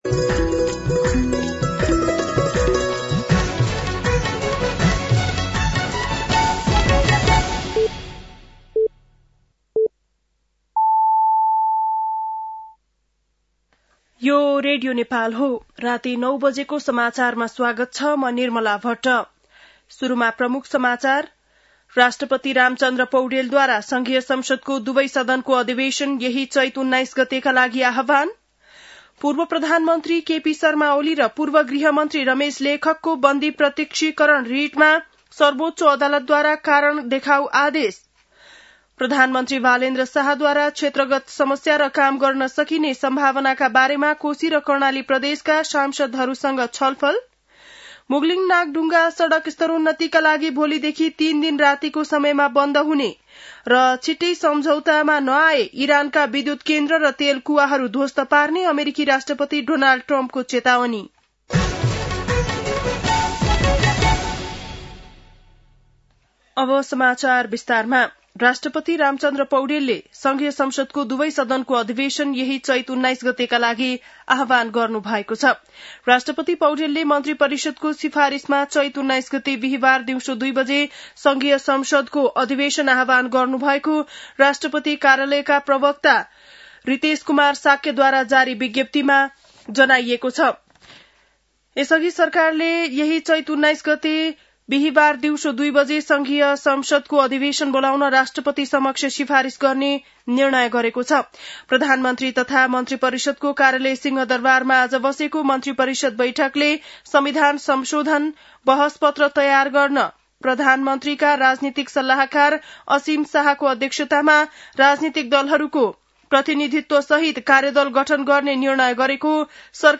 बेलुकी ९ बजेको नेपाली समाचार : १६ चैत , २०८२